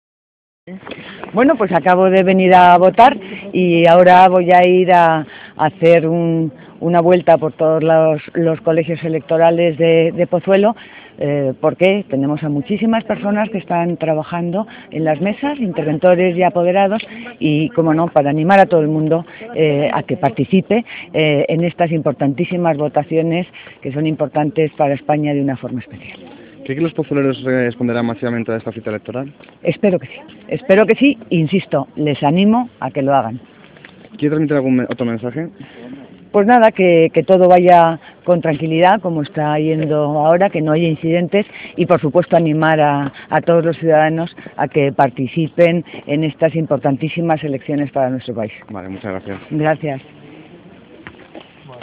Escuche las declaraciones de Adrados